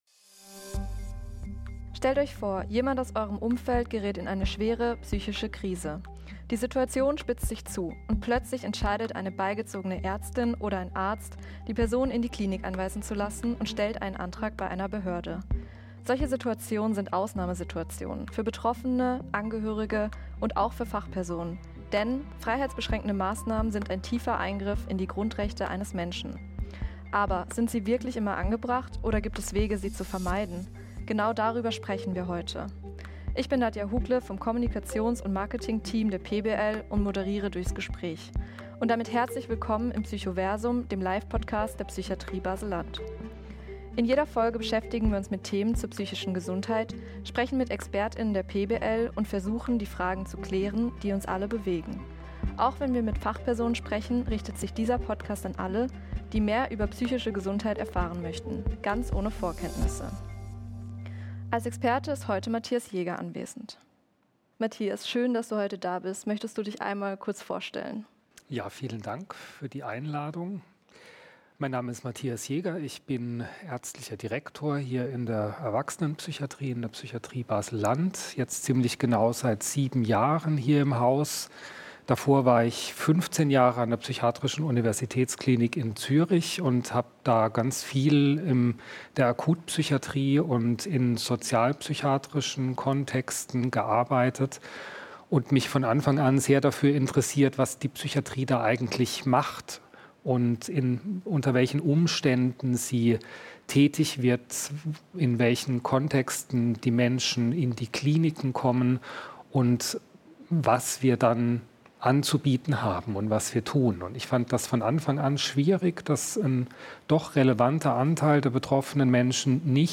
Im Zentrum steht die Frage, wie frühzeitige Prävention gelingen kann – bevor überhaupt über Zwangsmassnahmen nachgedacht werden muss. Die Diskussion beleuchtet rechtliche Grundlagen, gesellschaftliche Erwartungen an die Psychiatrie, Strukturen des Versorgungssystems sowie die Haltung der Fachpersonen, die täglich mit komplexen Entscheidungssituationen konfrontiert sind.